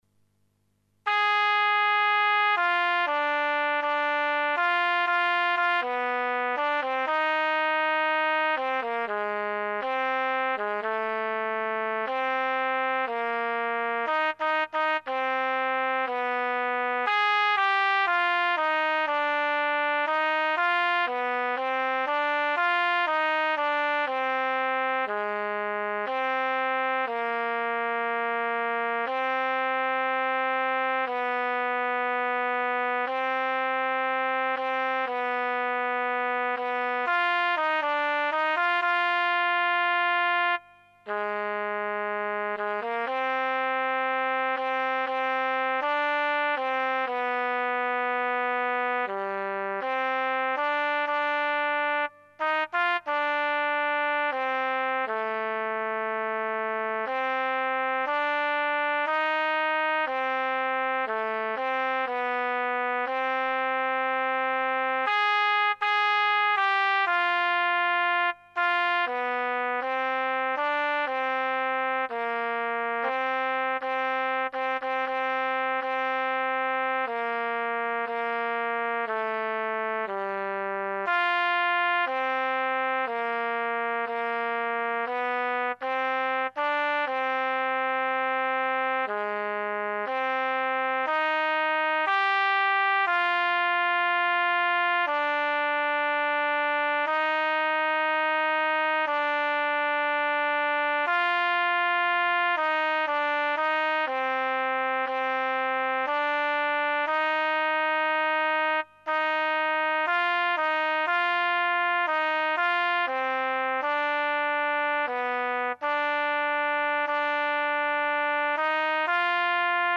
pour choeur de femmes à deux voix
là, la voix seule (à partir de la meure 17)